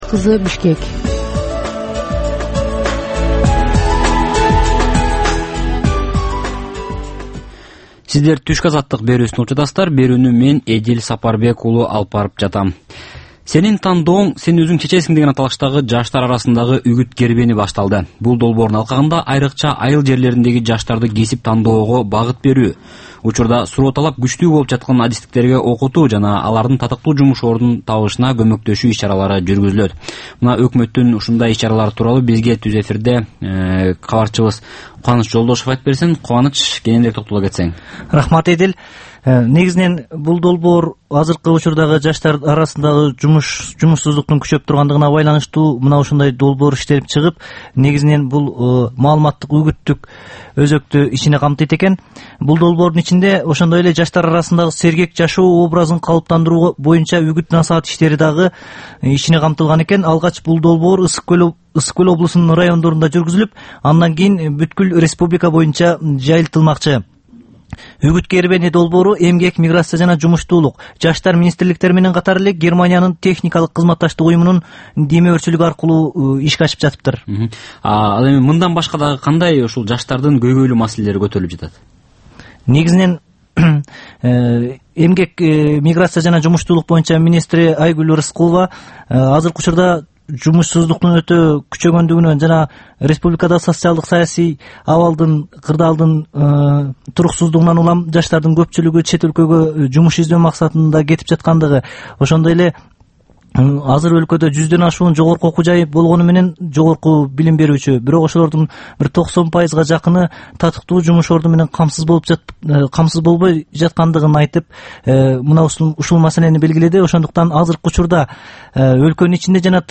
Бул түшкү үналгы берүү жергиликтүү жана эл аралык кабарлар, ар кыл орчун окуялар тууралуу репортаж, маек, талкуу, кыска баян жана башка оперативдүү берүүлөрдөн турат. "Азаттык үналгысынын" бул чак түштөгү алгачкы берүүсү Бишкек убакыты боюнча саат 12:00ден 12:15ке чейин обого чыгарылат.